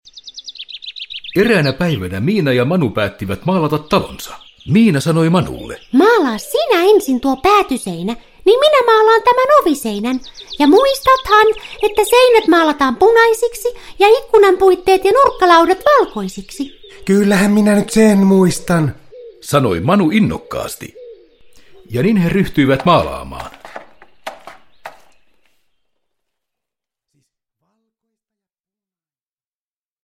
Miinan ja Manun kotiaskareita – Ljudbok – Laddas ner